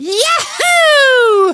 One of two voice clips from Mario in Super Mario Galaxy when he is launched.